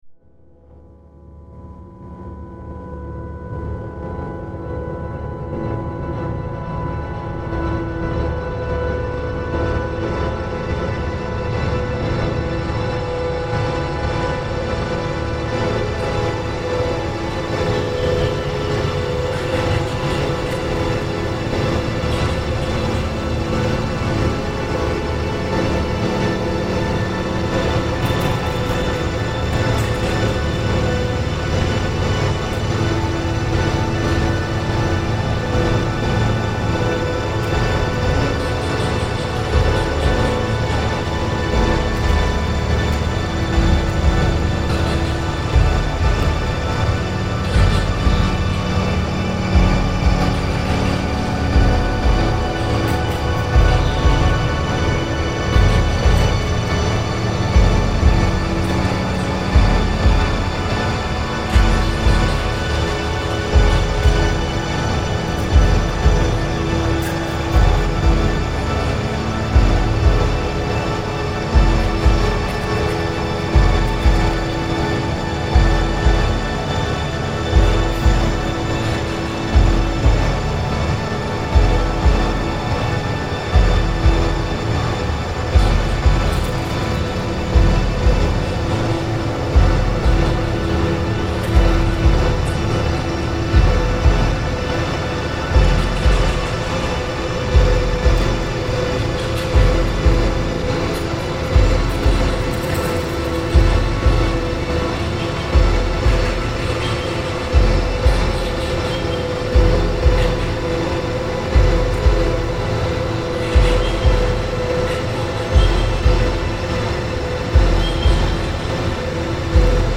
Dularcha Rail Tunnel, Mooloolah, Queensland, Australia